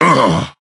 bo_hurt_02.ogg